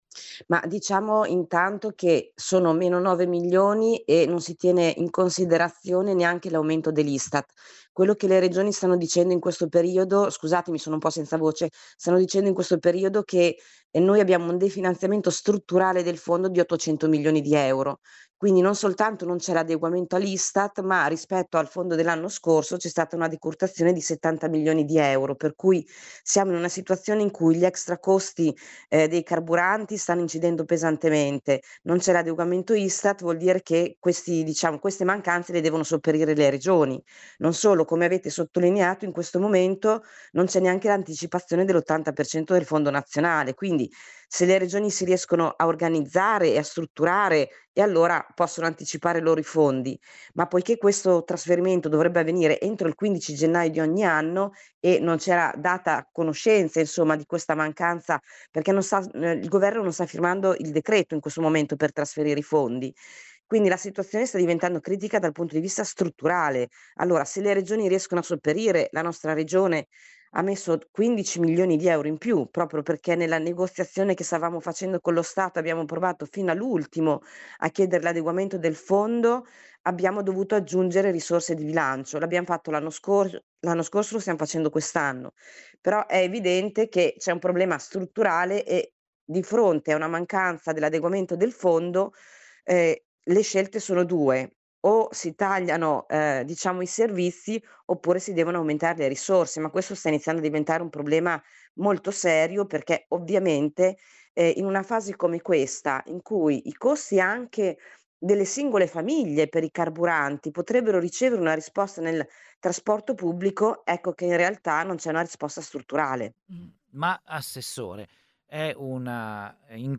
“O si tagliano i servizi o si aumentano i fondi” spiega Irene Priolo, assessora Trasporti dell’Emilia Romagna.